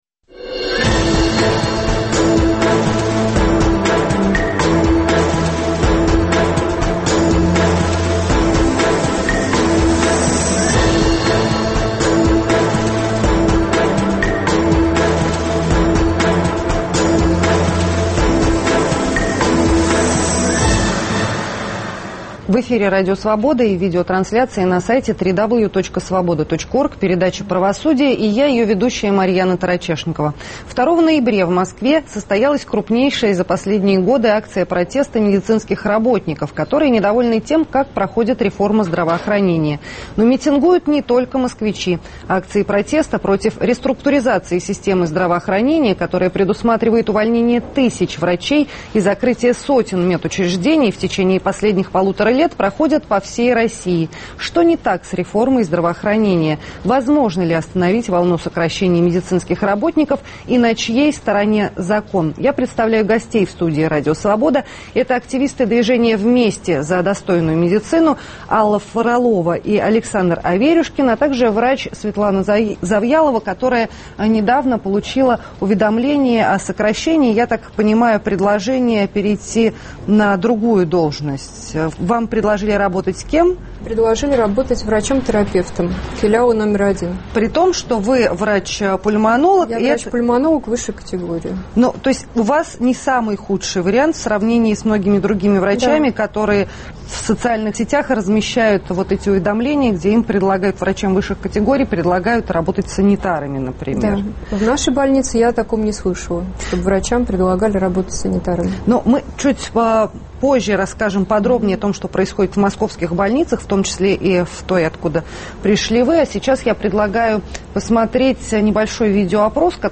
Медики и пациенты по всей России митингуют против реструктуризации системы здравоохранения, которая предусматривает увольнение тысяч врачей и закрытие сотен медучреждений. В студии РС